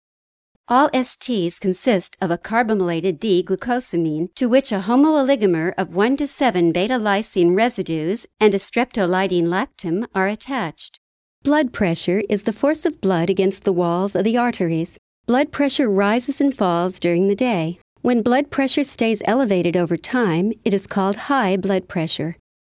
warm, clear diction, professional, expressive, engaging young adult, middle age, senior E-Learningk, Medical, Technical, Corporate training, educational, promotions, business, websites, audio books, children\'s stories, IVR, English language learning, real estate, travel logs narrations
mid-atlantic
Sprechprobe: Sonstiges (Muttersprache):